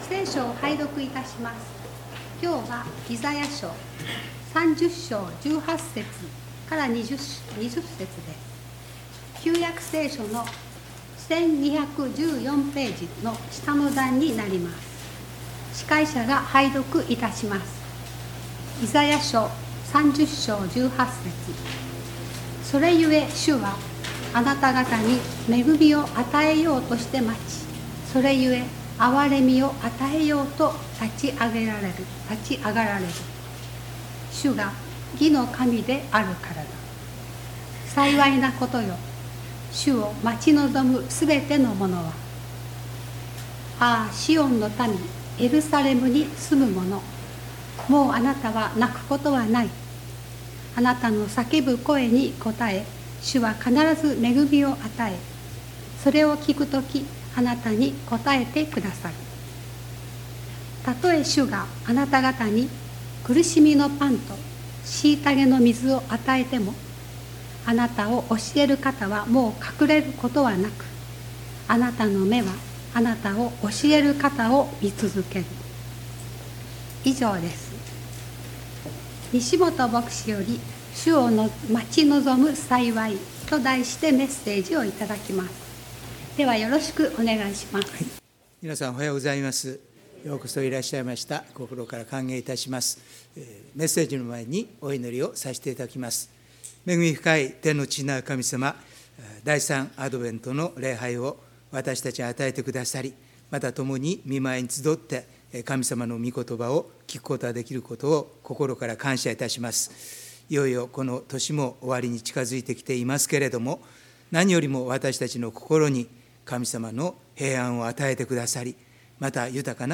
礼拝メッセージ「主を待ち望む幸い」│日本イエス・キリスト教団 柏 原 教 会